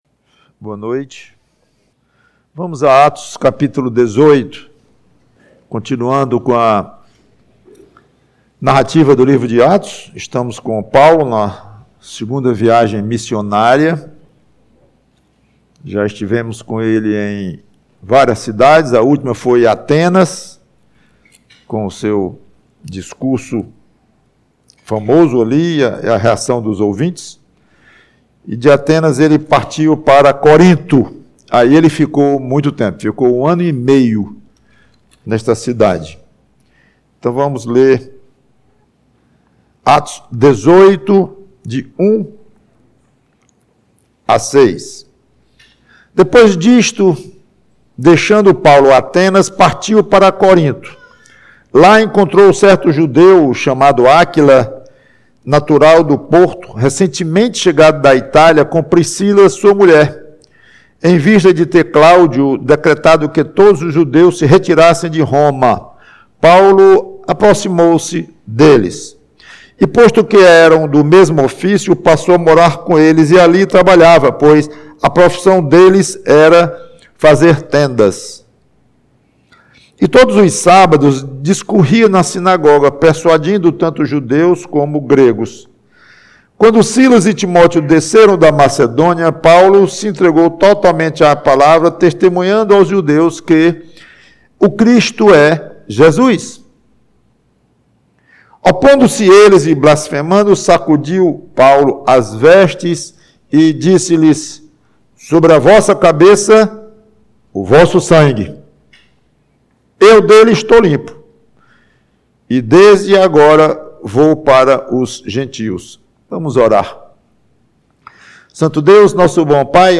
PREGAÇÃO Sua pregação é fria?